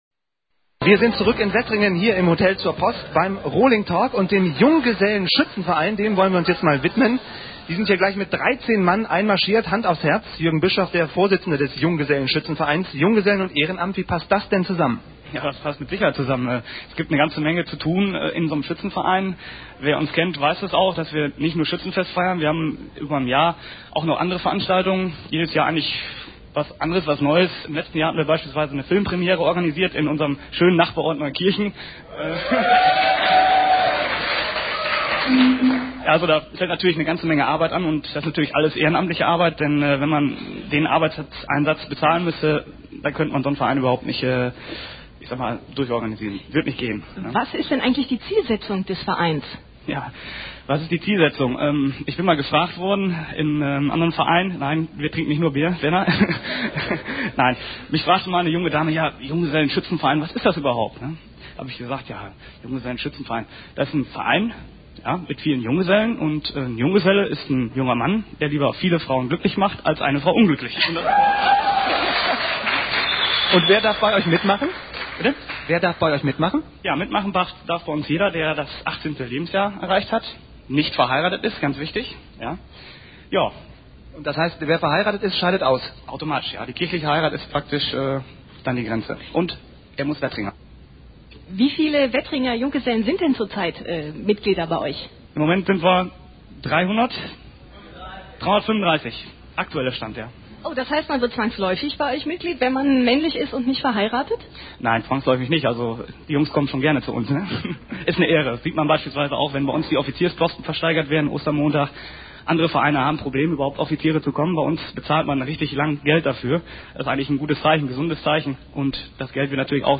Radiobeitrag (mp3 -mono- 752 kB, 6:42 min)